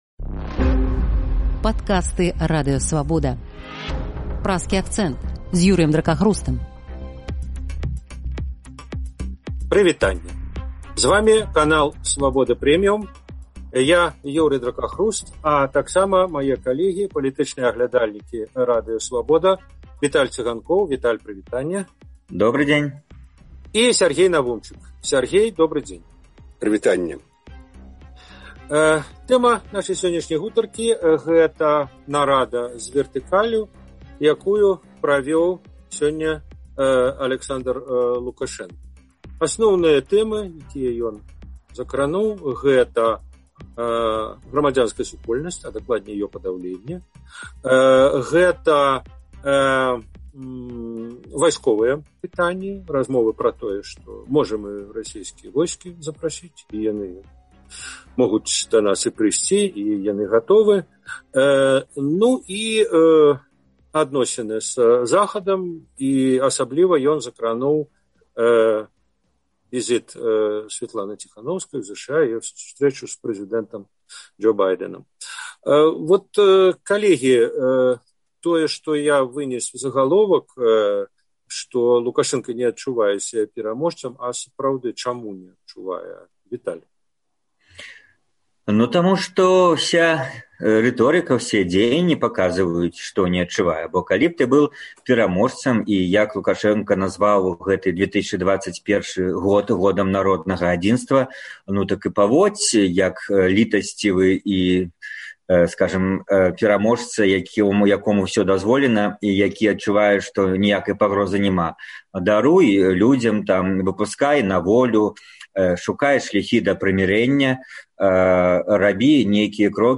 палітычныя аглядальнікі